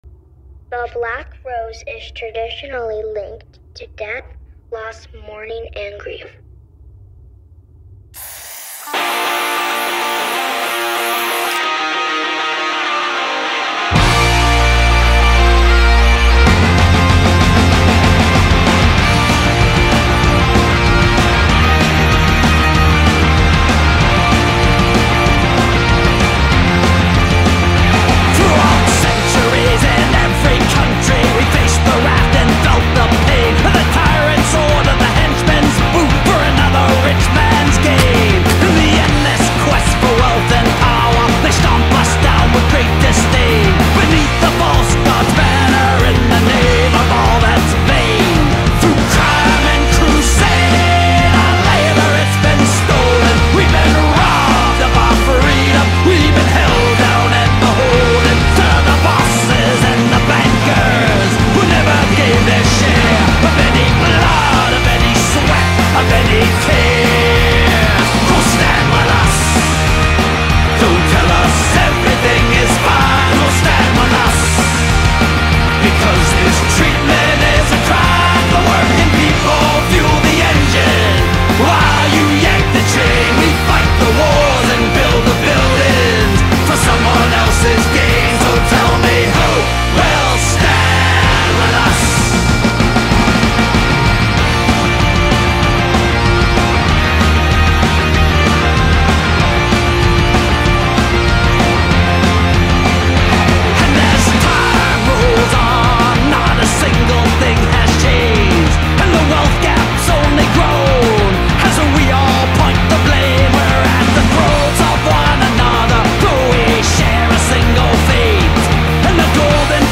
This one marches in like it owns the street.
There is grit here, but there is also heart.
Loud does not mean empty.